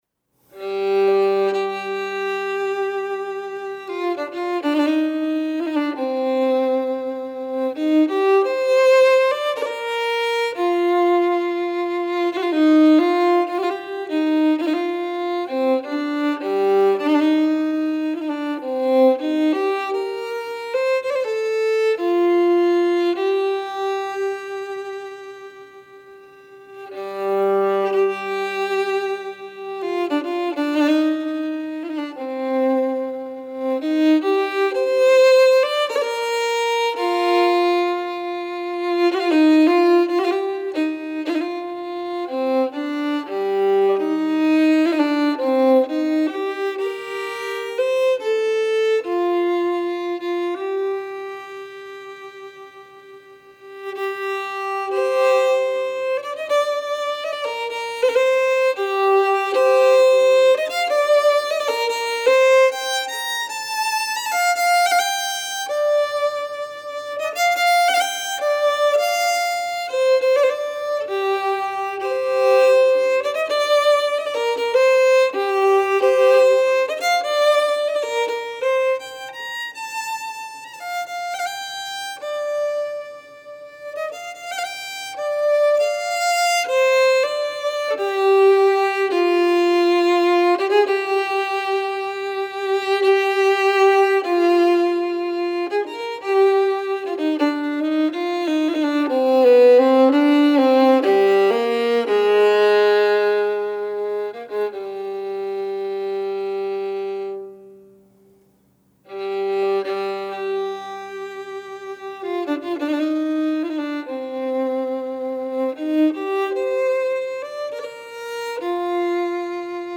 slow air